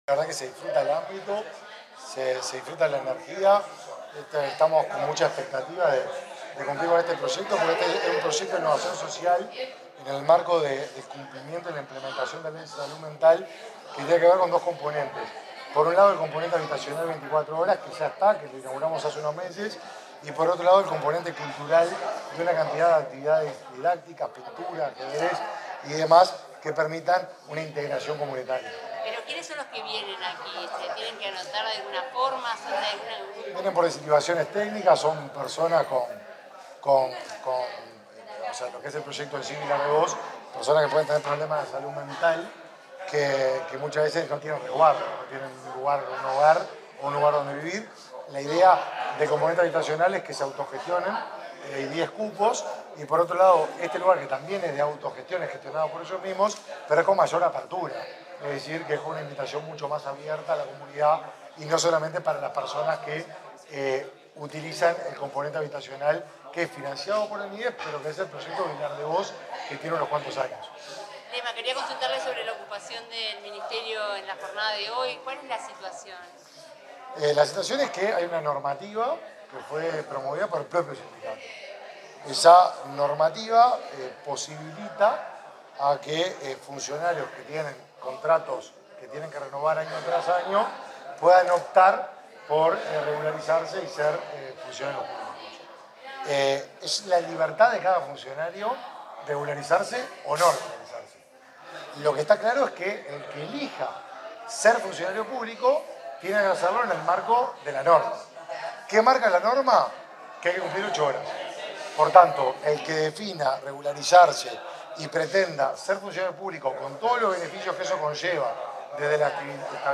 Declaraciones del ministro de Desarrollo Social, Martín Lema.
Declaraciones del ministro de Desarrollo Social, Martín Lema. 13/12/2023 Compartir Facebook X Copiar enlace WhatsApp LinkedIn Este 13 de diciembre, el ministro de Desarrollo Social, Martín Lema, participó en la inauguración de un espacio cultural de inclusión comunitaria del colectivo de Radio Vilardevoz. Al finalizar el evento, dialogó con la prensa.